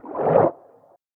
Fish_Attack1.ogg